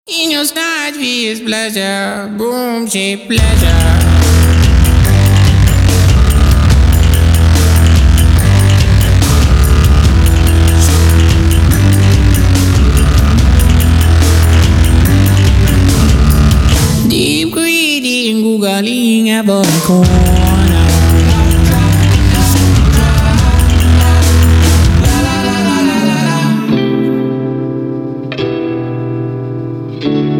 атмосферные
Industrial rock
alternative
indie rock
experimental
Indietronica